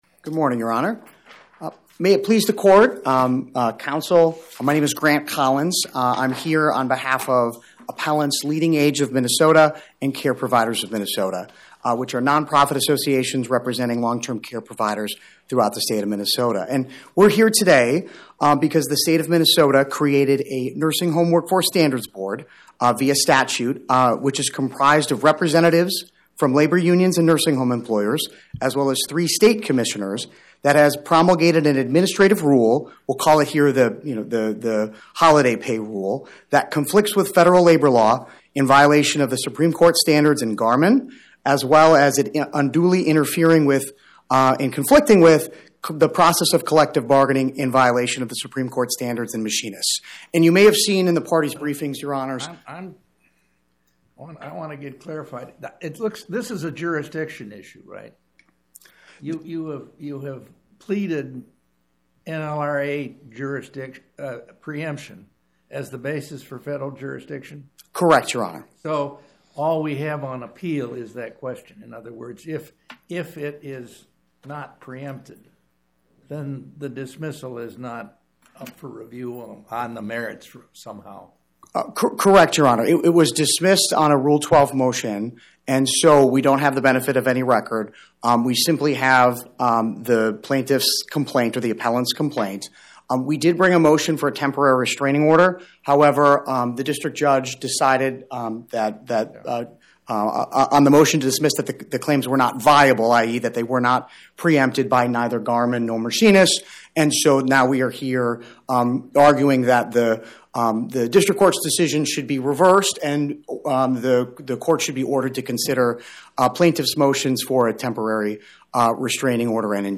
My Sentiment & Notes 25-2252: LeadingAge Minnesota vs Nicole Blissenbach Podcast: Oral Arguments from the Eighth Circuit U.S. Court of Appeals Published On: Wed Feb 11 2026 Description: Oral argument argued before the Eighth Circuit U.S. Court of Appeals on or about 02/11/2026